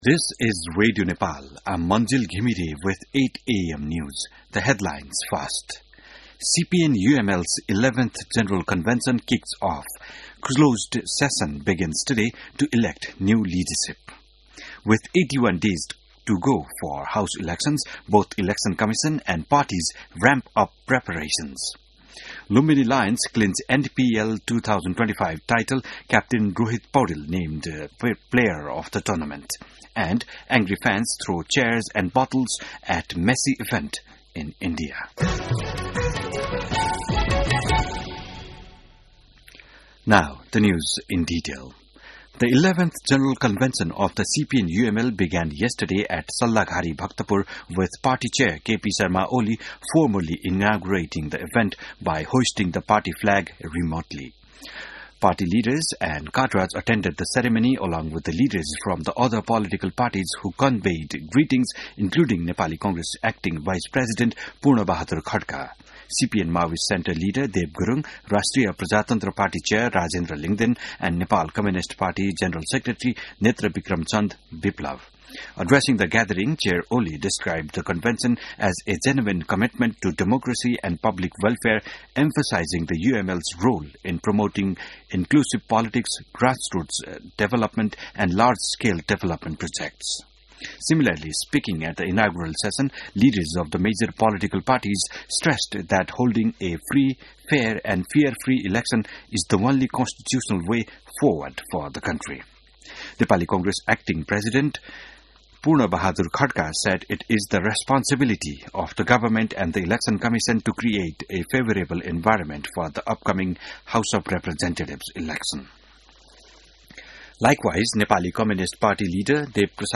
An online outlet of Nepal's national radio broadcaster
बिहान ८ बजेको अङ्ग्रेजी समाचार : २८ मंसिर , २०८२